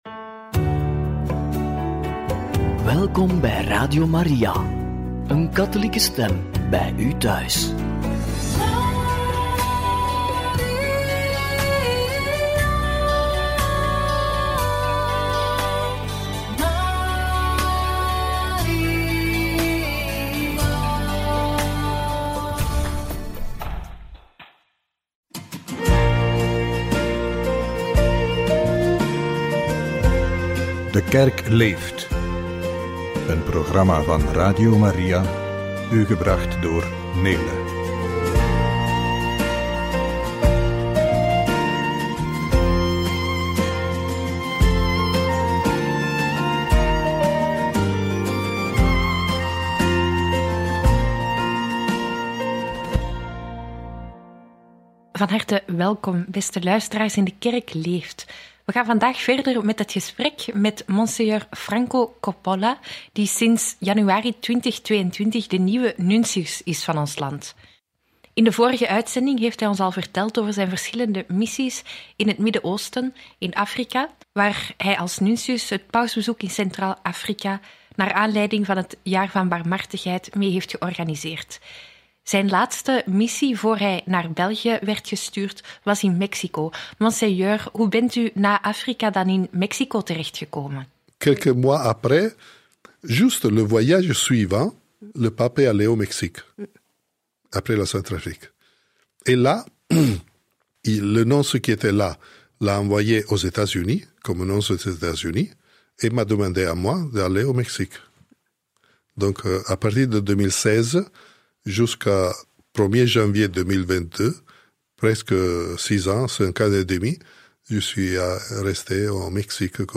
Gesprek met Nuntius Franco Coppola over Onze Lieve Vrouw en zijn taak in België – Radio Maria